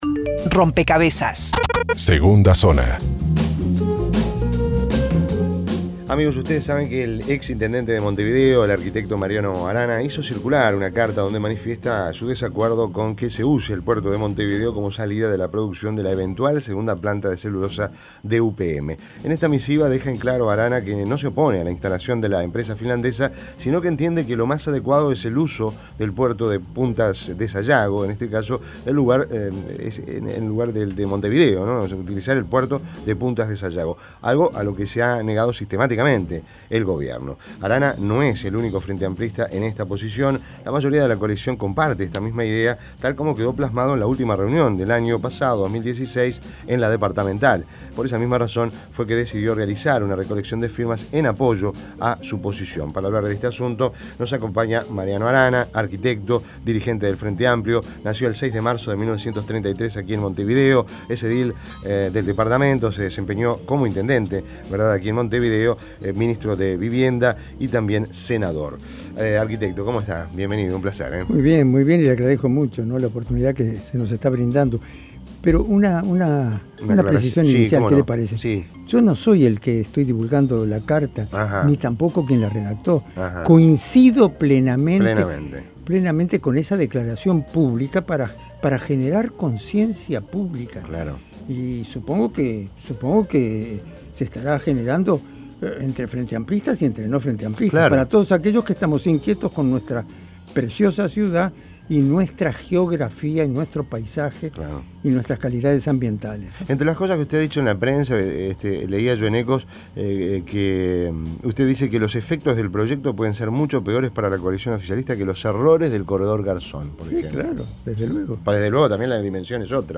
Entrevista en Rompkbzas